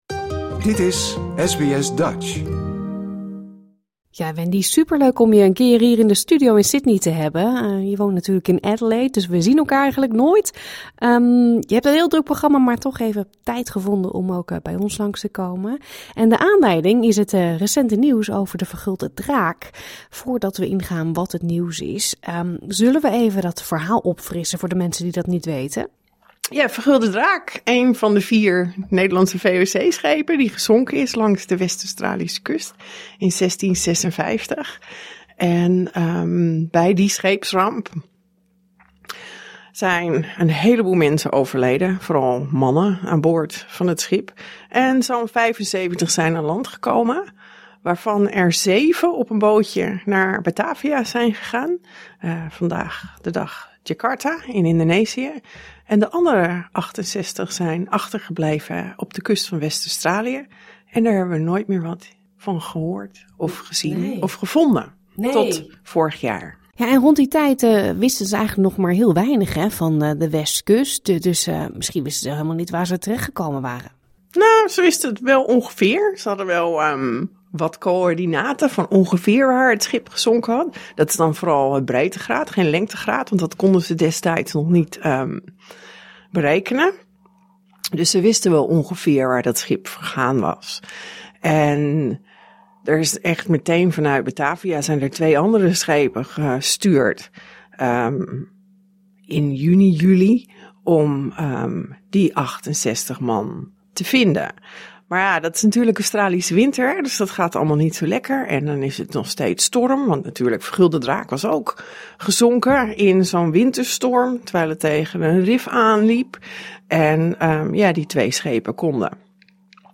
kwam langs in de studio om te vertellen over deze bijzondere ontdekking.